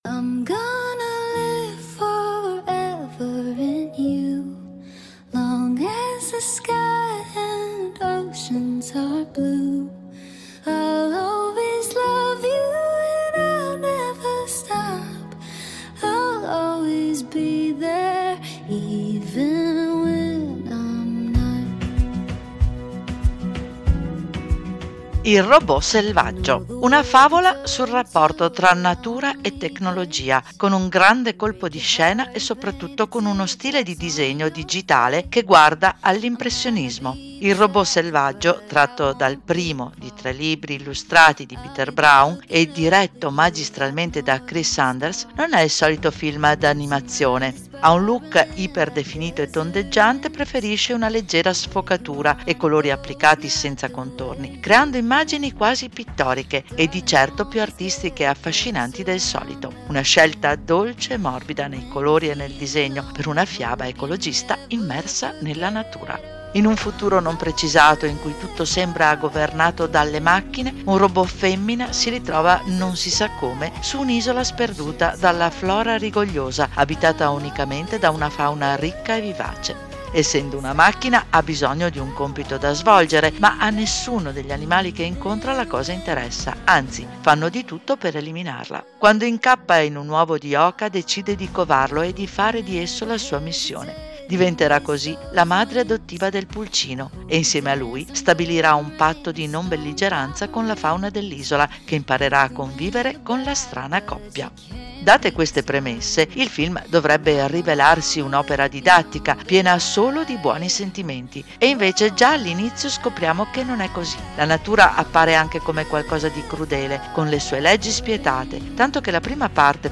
LA PRESENTAZIONE